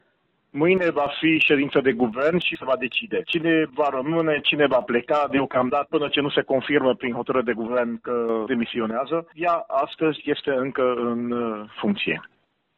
În legătură cu decizia Marei Togănel, subprefectul de Mureș Nagy Zsigmond a declarat: